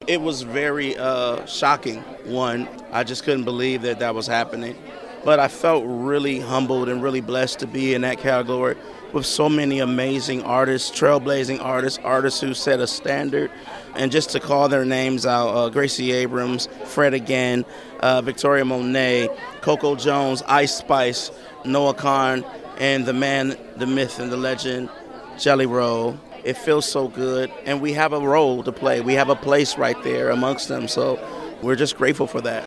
Audio / The War And Treaty's Michael Trotter Jr. talks about the Best New Artist nominations at this year's GRAMMY Awards.